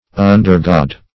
undergod - definition of undergod - synonyms, pronunciation, spelling from Free Dictionary
Undergod \Un"der*god`\, n.